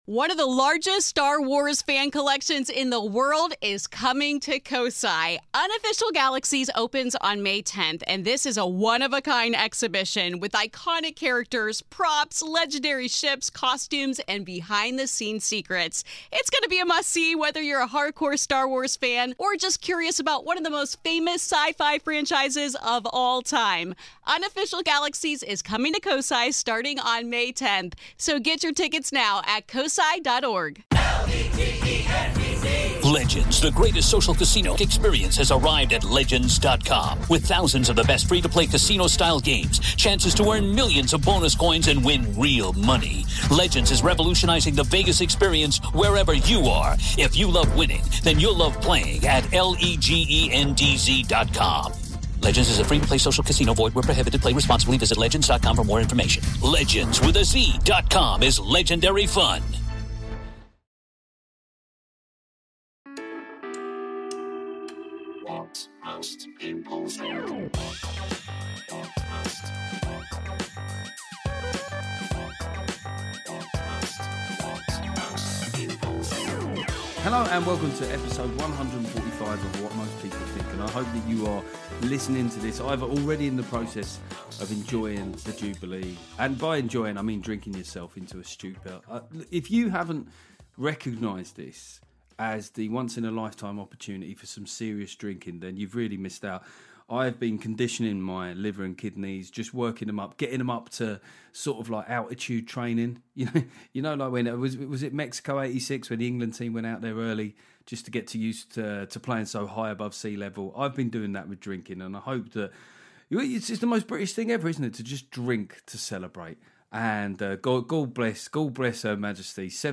After our usual catch-up I have a lovely chat with Husband / Wife comedy partners